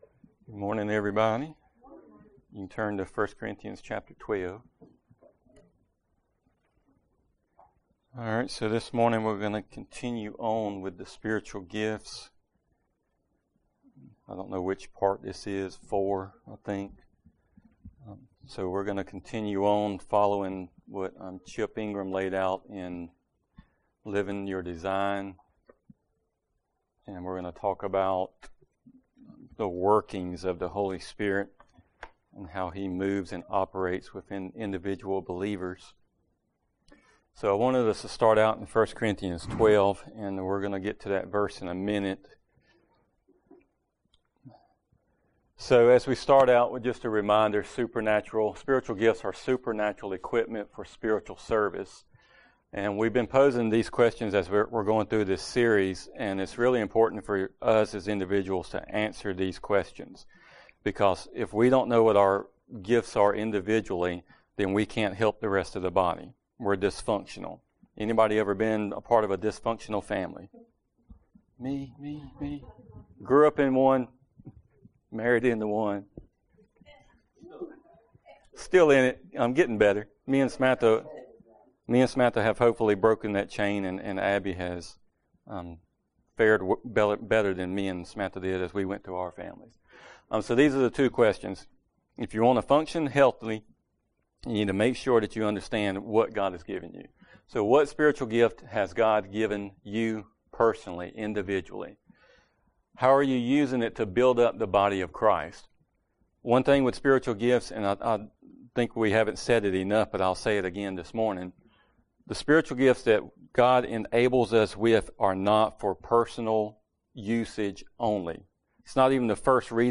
Passage: 1 Corinthians 12:4-7 Service Type: Sunday Morning Related Download Files Notes Topics